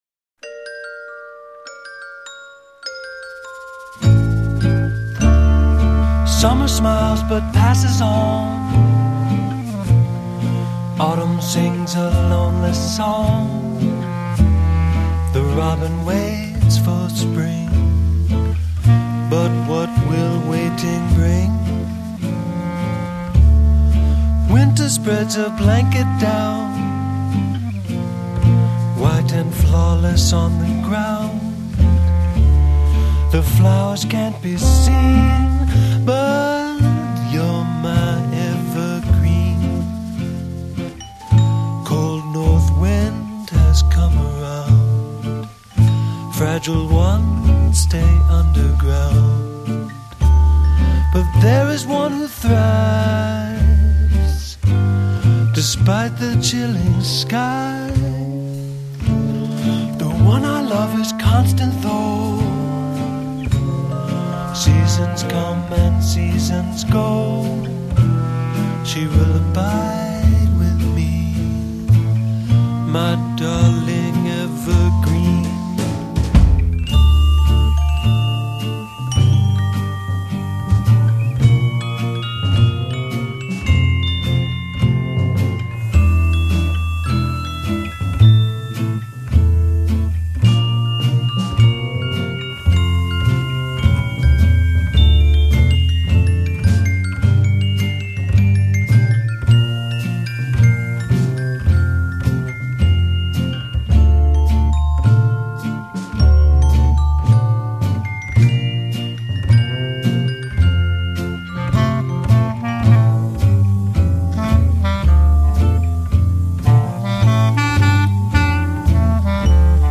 Something a bit offbeat.